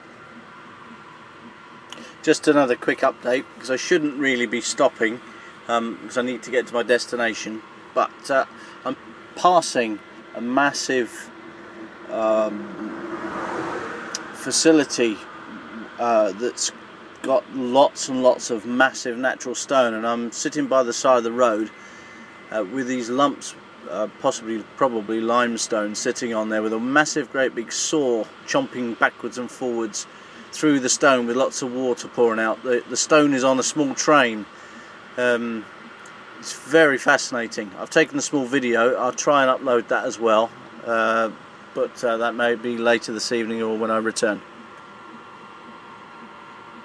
Natural stone being cut